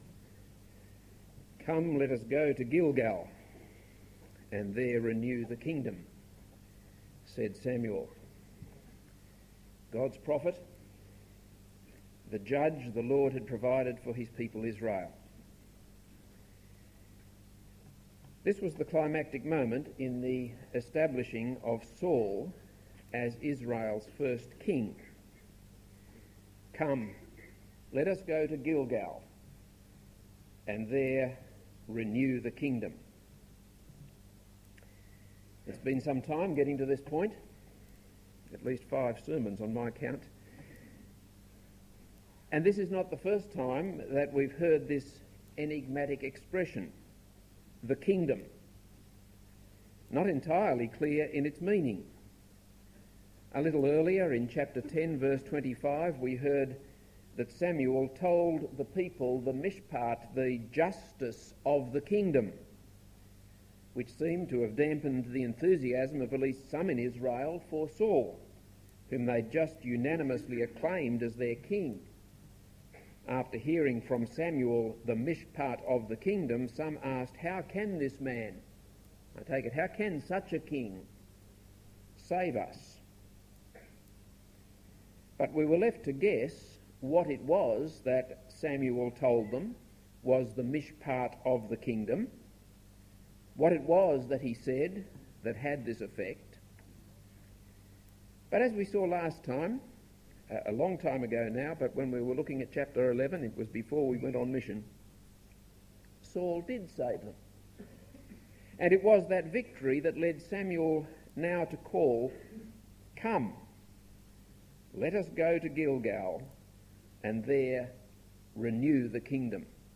This is a sermon on 1 Samuel 12.